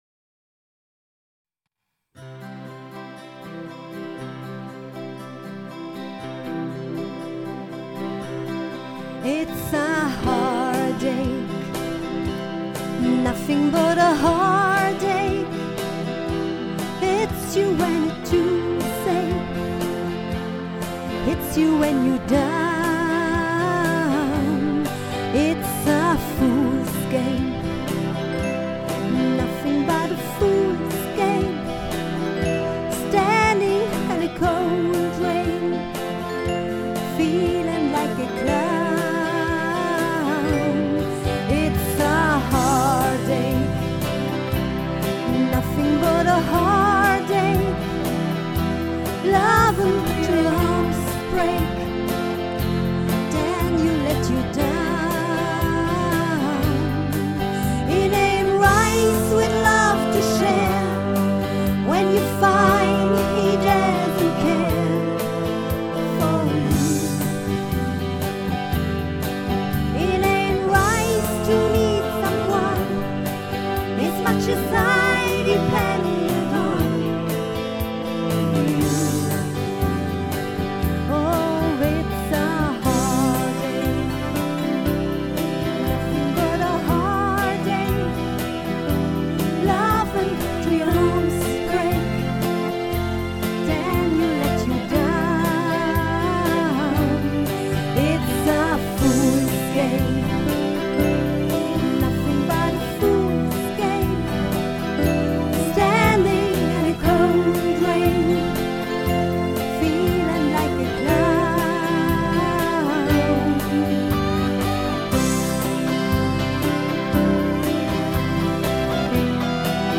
Tanzmusik mit Herz und Leidenschaft - Ihre Partyband
Schlager, Pop, Oldies, Country, Rock’n Roll, Rock, Folk